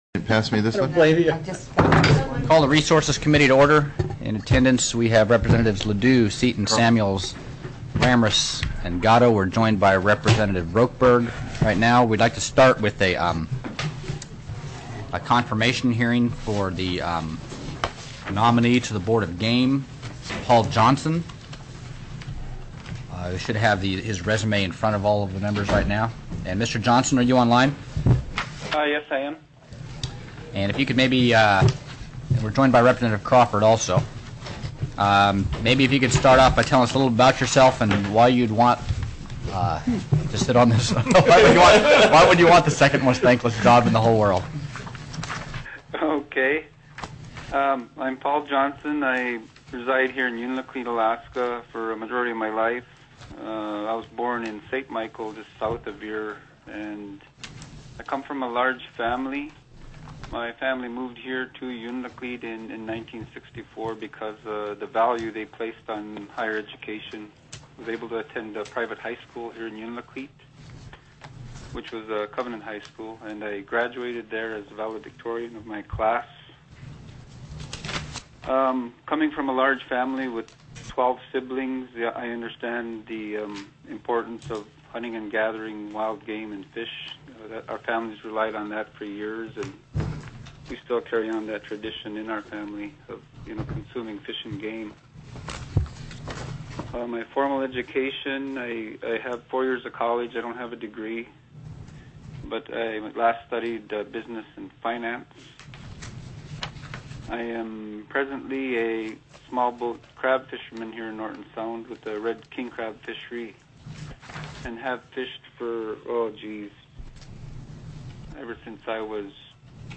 CONFIRMATION HEARING(S)